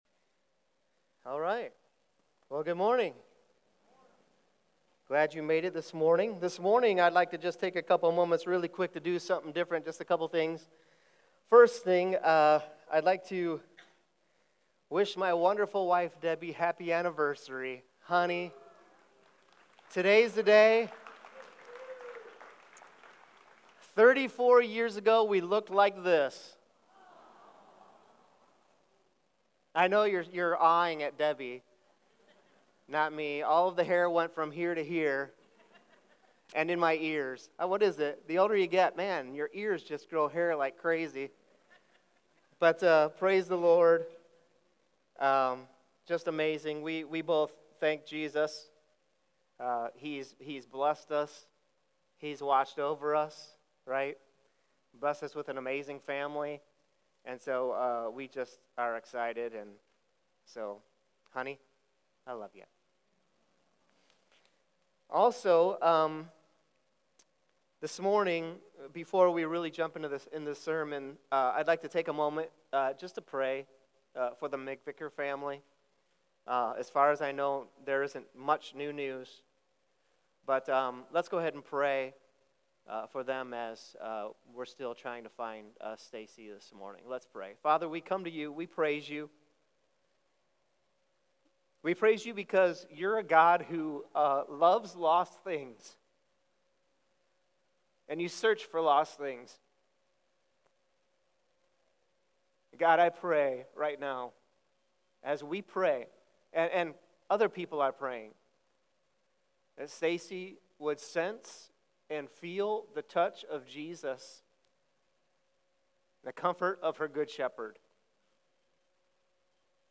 Sermon Questions Read John 12:1-8 & Luke 10:38-42.